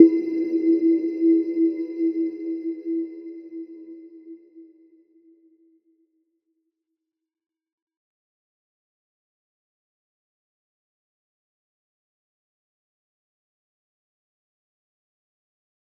Little-Pluck-E4-f.wav